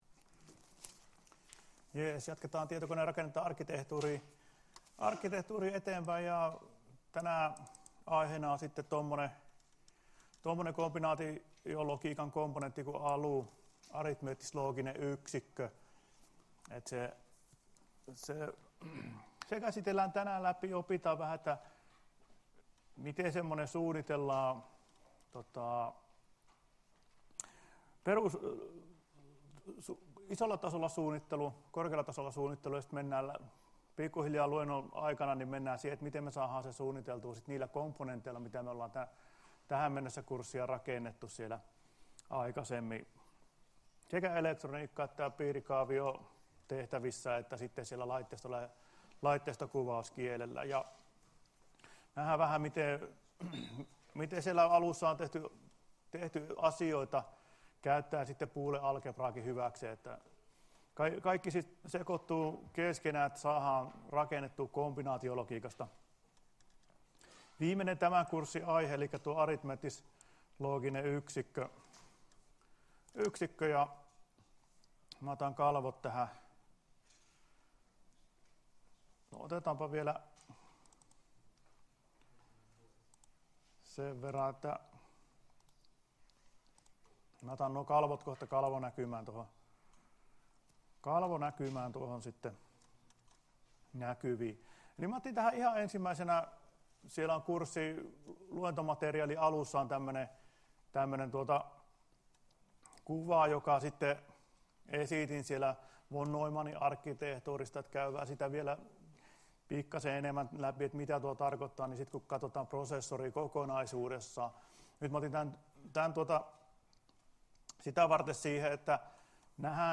Luento 21.11.2018